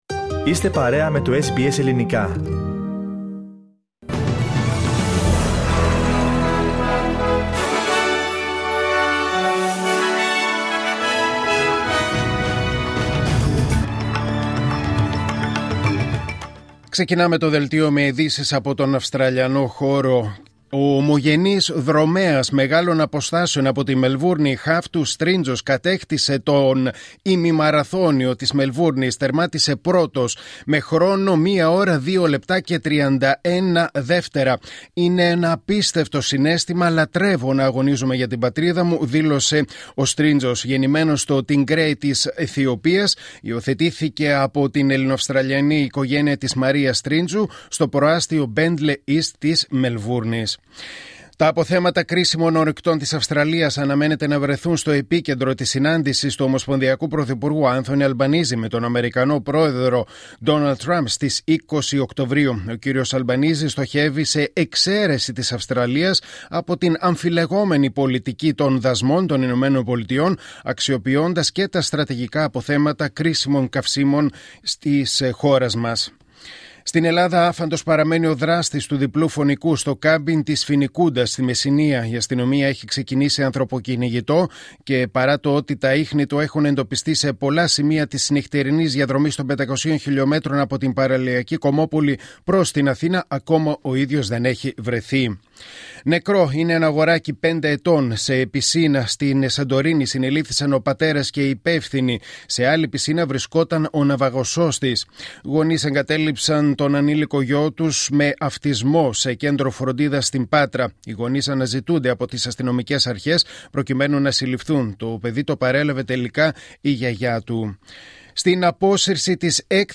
Δελτίο Ειδήσεων Κυριακή 12 Οκτωβρίου 2025